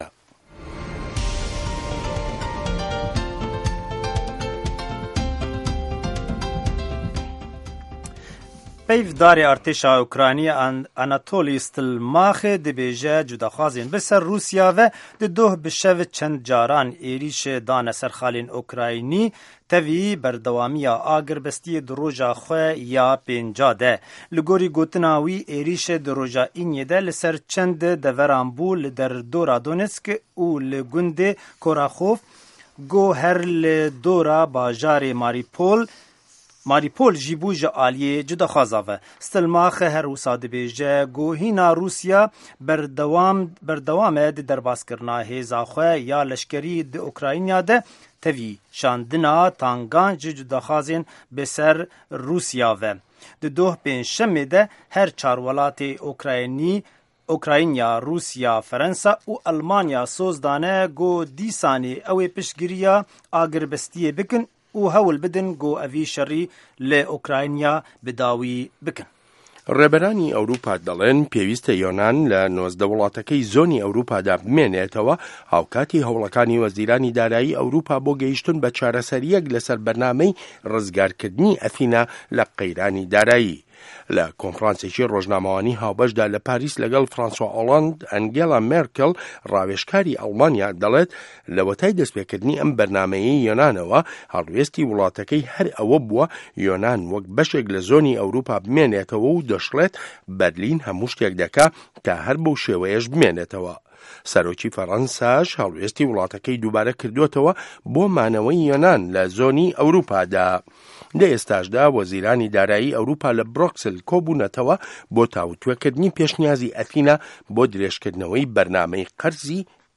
هه‌واڵه‌کان، ڕاپـۆرت، وتووێژ، سه‌رگوتاری ڕۆژانه‌‌ که‌ تیایدا ڕاوبۆچوونی حکومه‌تی ئه‌مه‌ریکا ده‌خرێته‌ ڕوو.